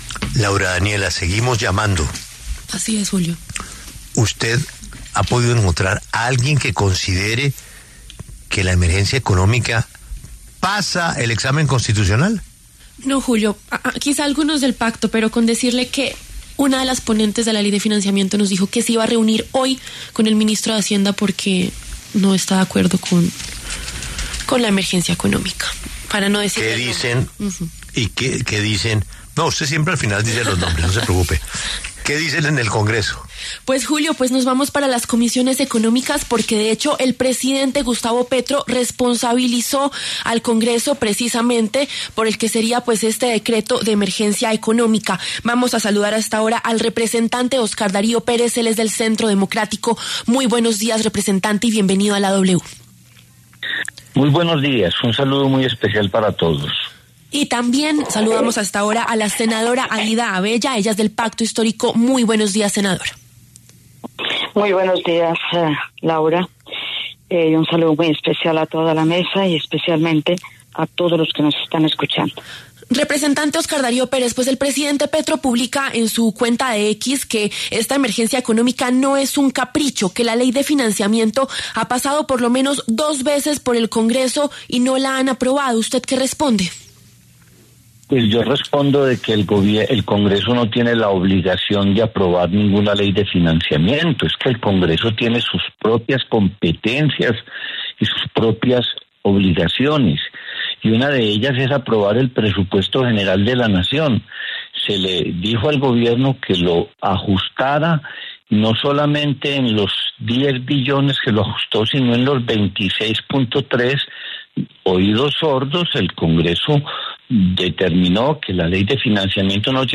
La W conversó con los congresistas Óscar Darío Pérez y Aída Avella, quienes debatieron sobre este decreto de emergencia económica.
Debate de congresistas: ¿Es inconstitucional la emergencia económica del Gobierno Petro?